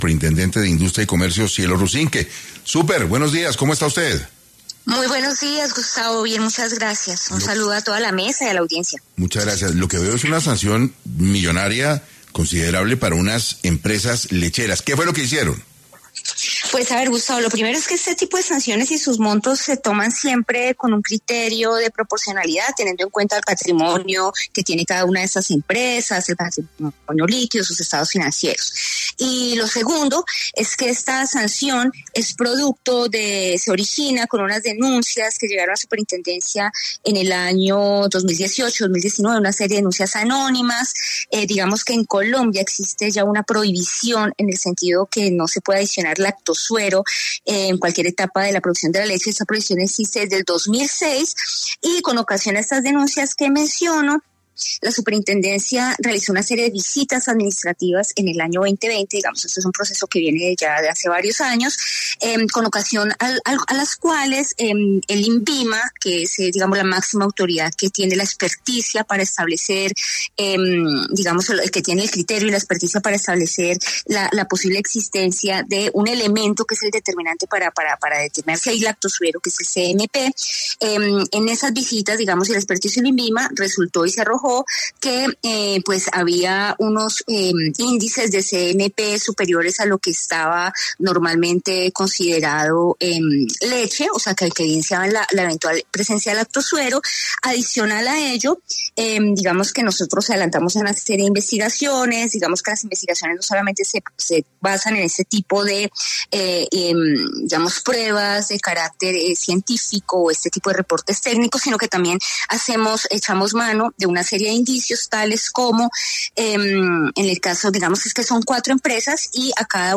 La superintendente de Industria y Comercio, Cielo Rusinque, habló en 6AM sobre cuáles fueron las infracciones cometidas por las empresas.